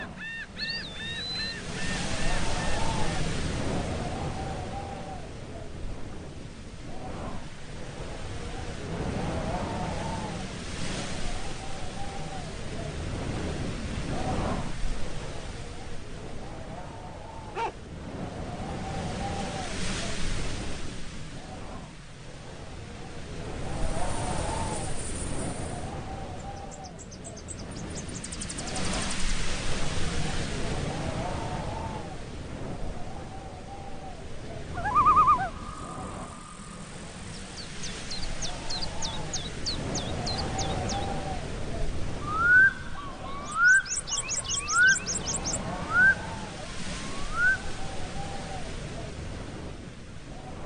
Nature sounds are recorded & designed to help people sleep, allowing you to relax and enjoy the sounds of nature while you rest or focus, with no adverts or interruptions.
Perfect for their masking effects, they are also helpful for people suffering with tinnitus.
Autumn-In-The-Forest-Sample.mp3